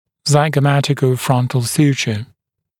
[ˌzaɪgəˌmætɪkəu’frʌntl ‘s(j)uːʧə]